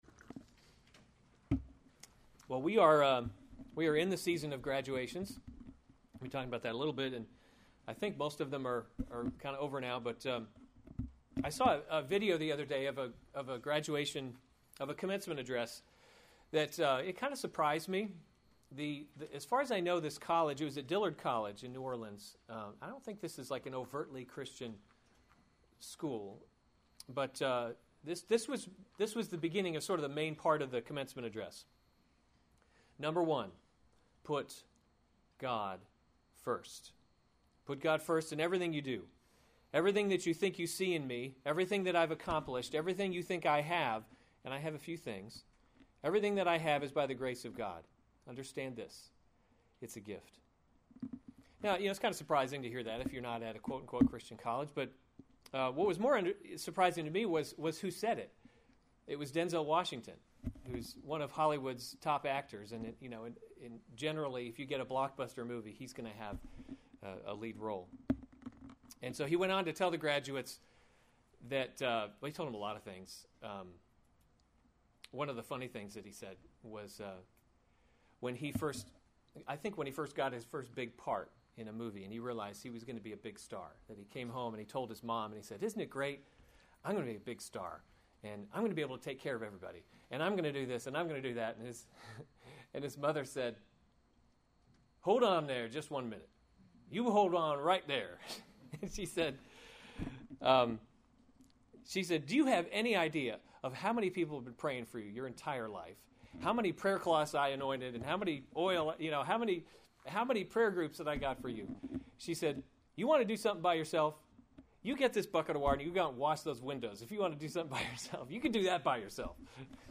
June 20, 2015 Psalms – Summer Series series Weekly Sunday Service Save/Download this sermon Psalm 16 Other sermons from Psalm You Will Not Abandon My Soul A Miktam [1] of David.